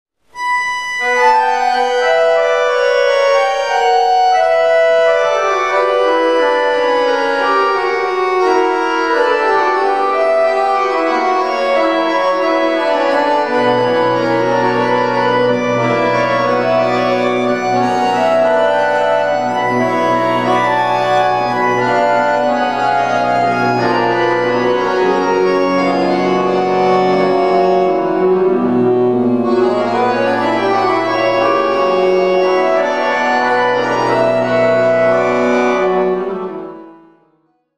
Praha - Santoška, kostel Církve českobratrské evangelické 10.12.2006
(Technické informace o nahrávce: kamera VHS se stereo mikrofonem, automatická regulace úrovně nahrávání, vzdálenost od zdroje min. 20 m)